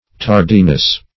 tardiness - definition of tardiness - synonyms, pronunciation, spelling from Free Dictionary
Tardiness \Tar"di*ness\, n.